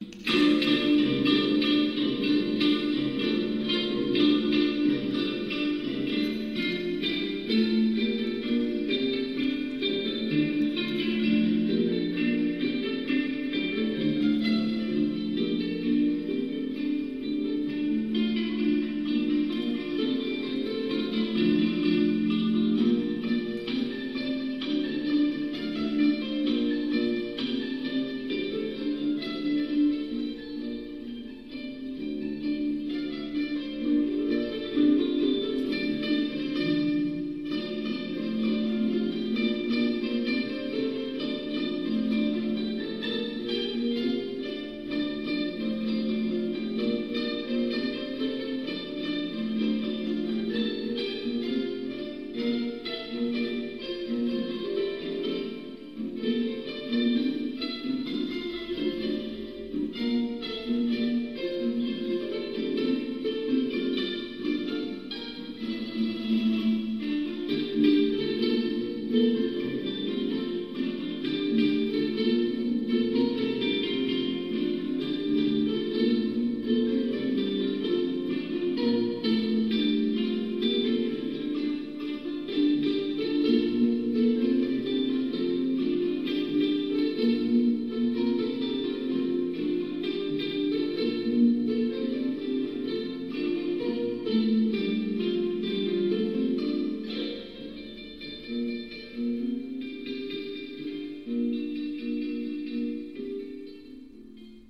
箏1
箏2
十七絃